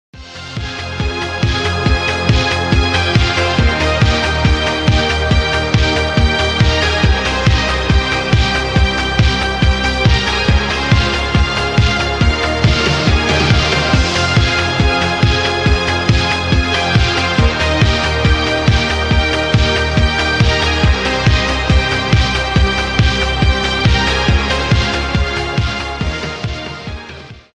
• Качество: 128, Stereo
без слов
synthwave